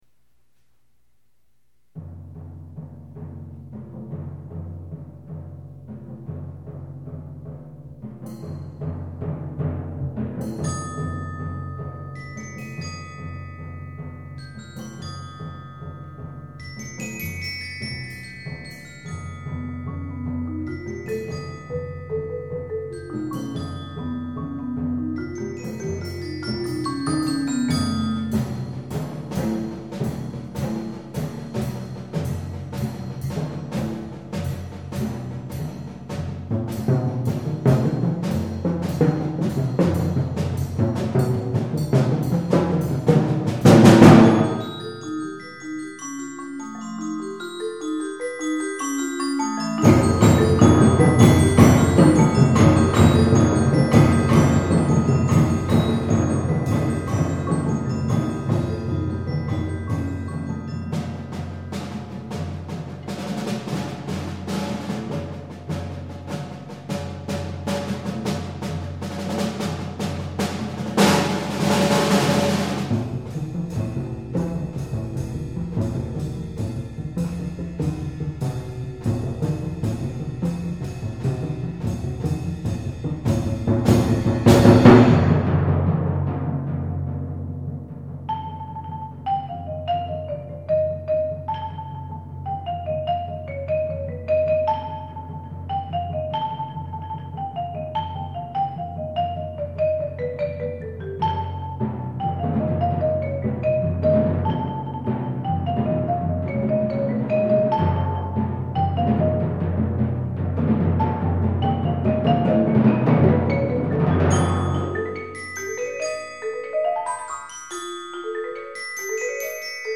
Genre: Percussion Ensemble
Percussion 1 (bells, crotales)
Percussion 2 (4-octave marimba, 3-octave vibraphone)
Percussion 3 (snare drum, 4.3-octave marimba)
Percussion 4 (4 tom-toms, high triangle)
Percussion 6 (timpani, cowbell)